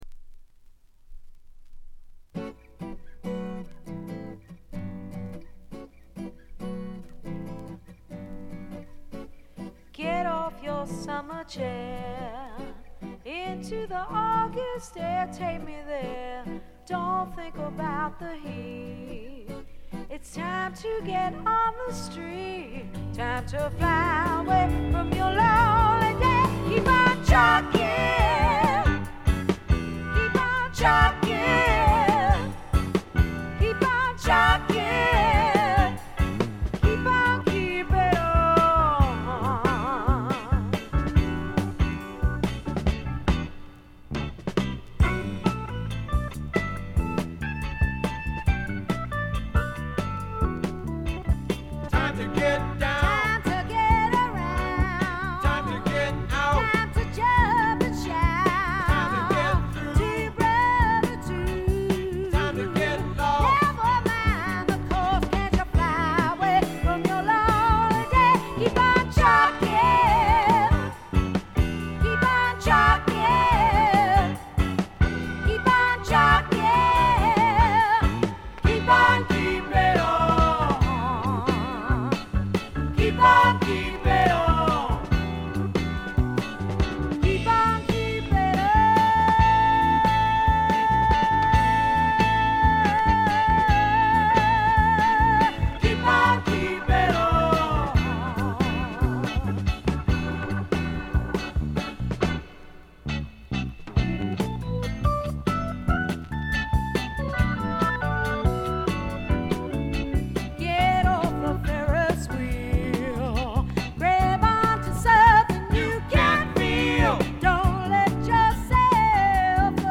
これ以外はほとんどノイズ感無し。
基本は軽いスワンプ路線。
試聴曲は現品からの取り込み音源です。